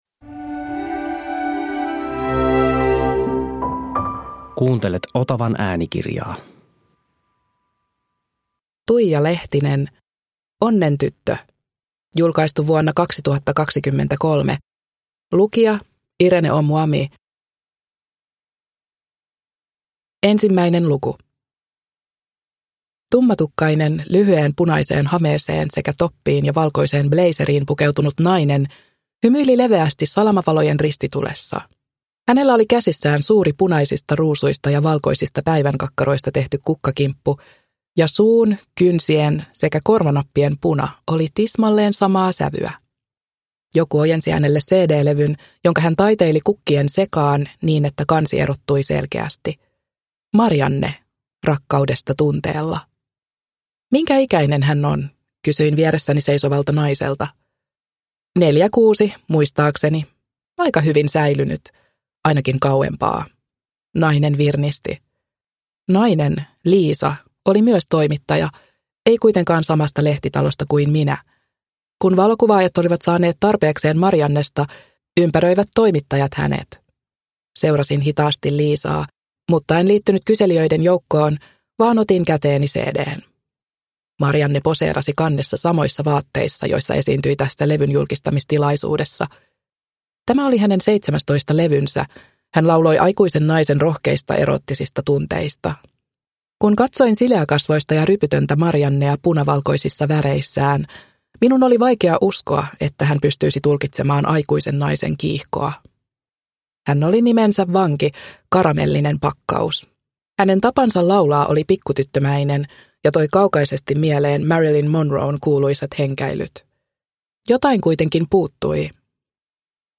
Onnentyttö (ljudbok) av Tuija Lehtinen